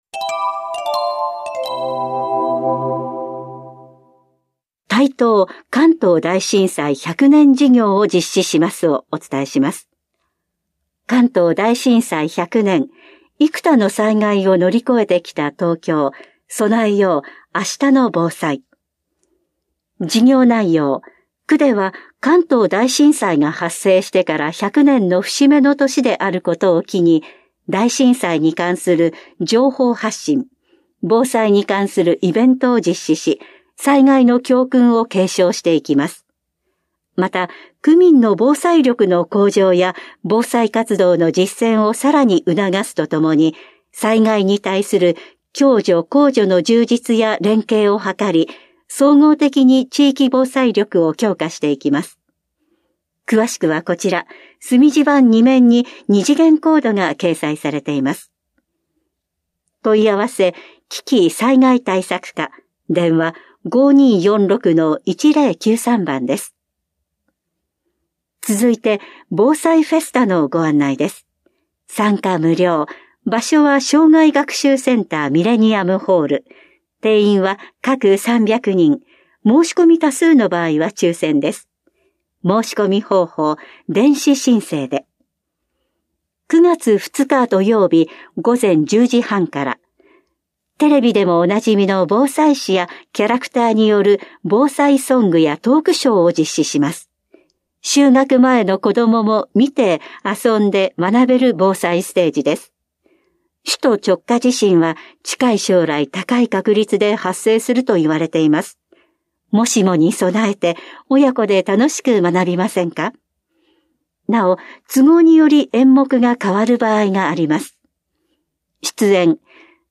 広報「たいとう」令和5年7月20日号の音声読み上げデータです。